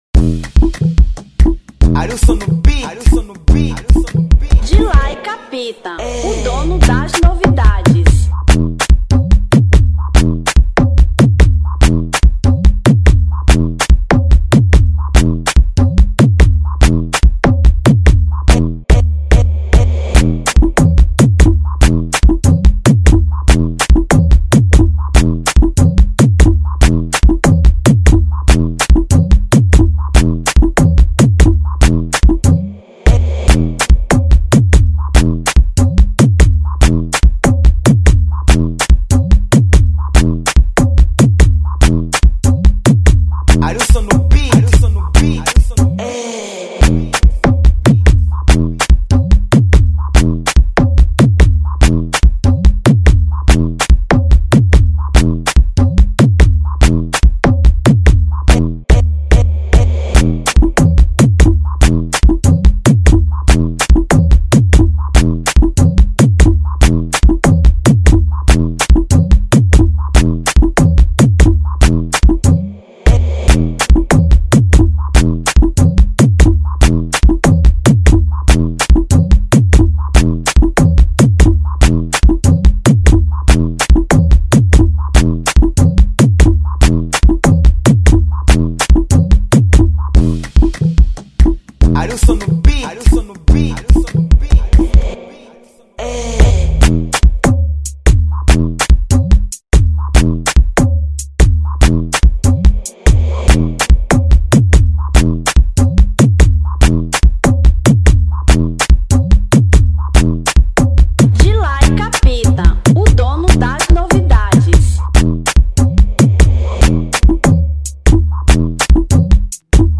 Instrumental 2025